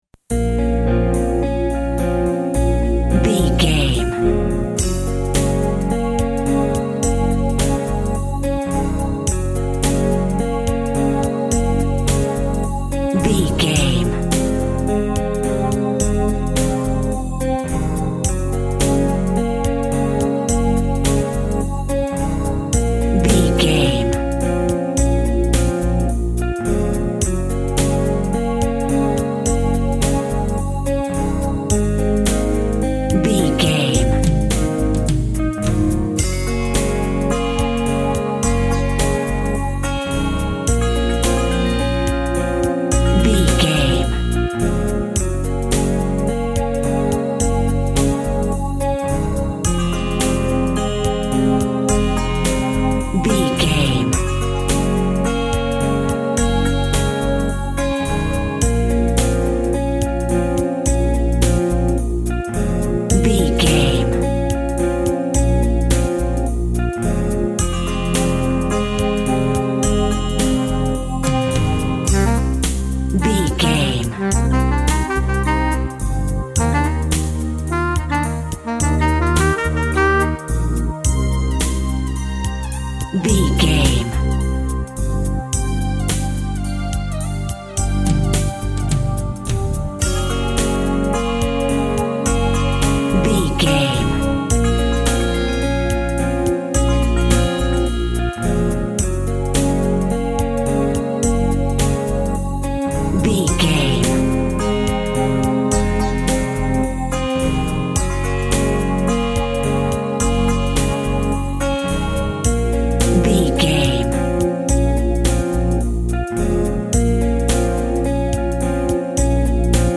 Aeolian/Minor
B♭
groovy
dreamy
smooth
melancholy
drums
electric guitar
synthesiser
synth bass
synth lead